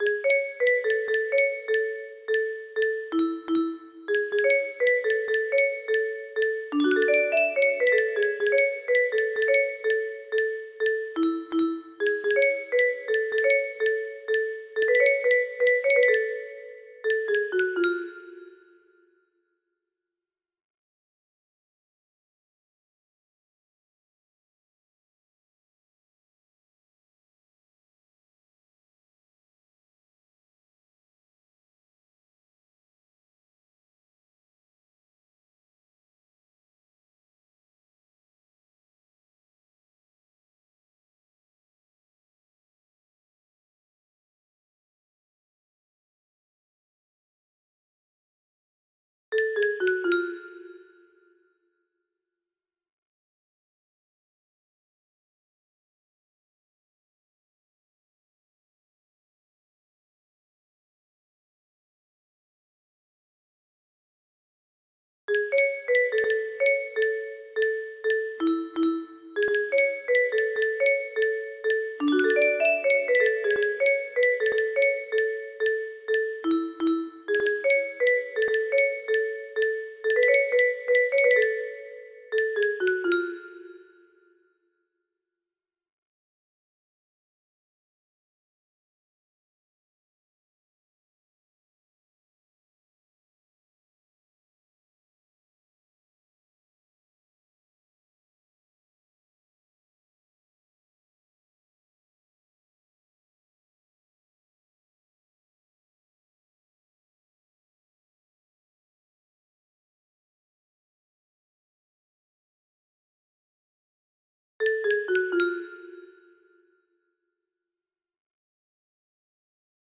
BPM 125